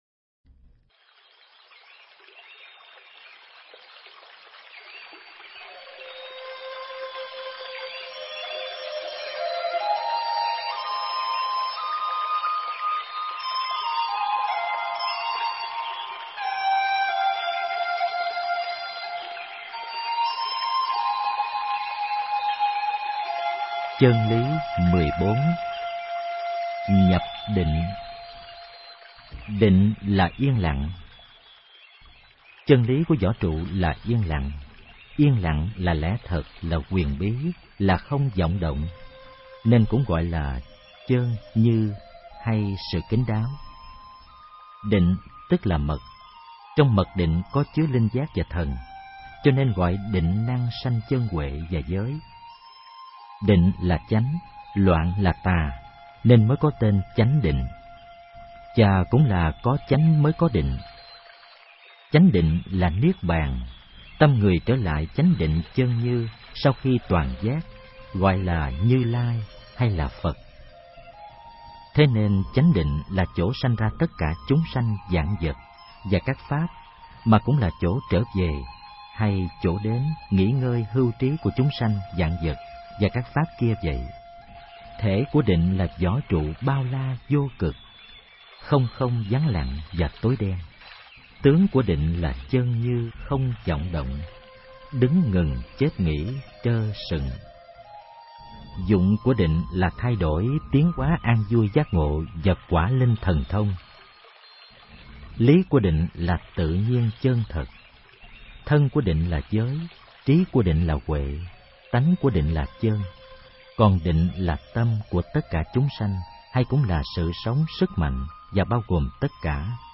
Nghe sách nói chương 14. Nhập Định